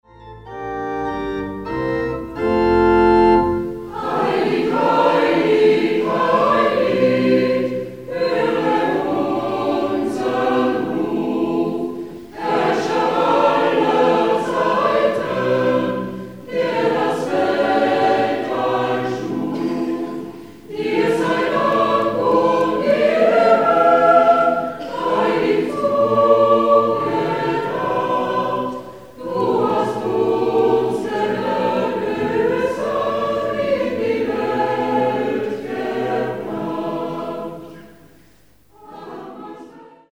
(Live-Aufnahmen)